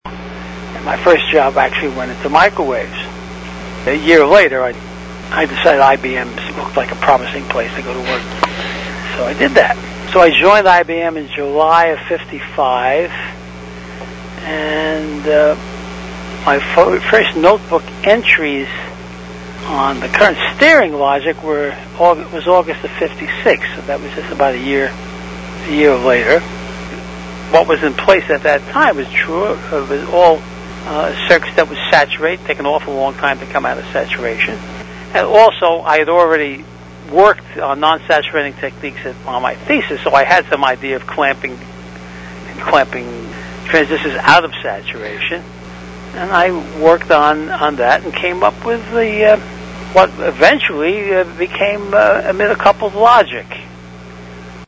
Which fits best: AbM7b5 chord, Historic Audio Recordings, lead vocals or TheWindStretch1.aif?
Historic Audio Recordings